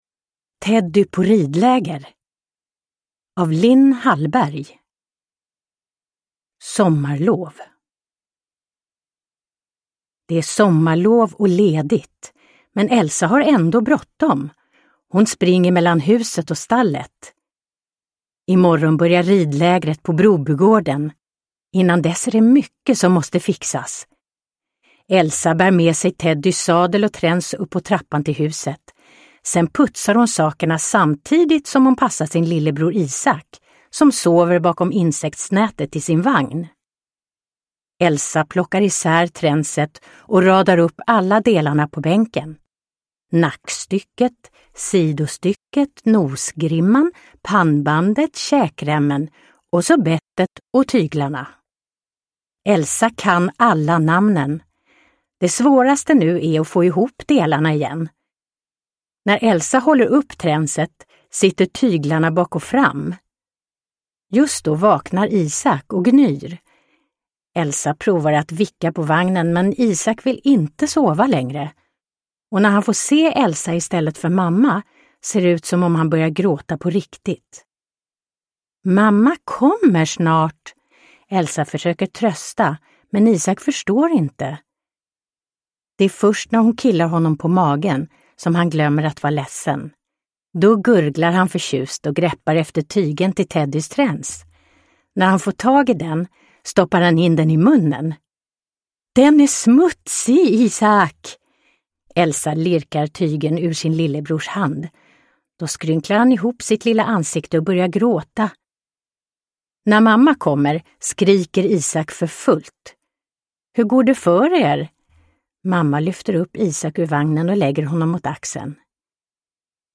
Teddy på ridläger – Ljudbok – Laddas ner